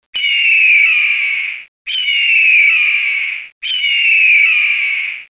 Звуки орла
На этой странице собраны разнообразные звуки орла: от пронзительных криков до низкого клекота.
Орел кричит - чистый звук nКрик орла - очищенный звук nОчищенный звук крика орла nЧистый крик орла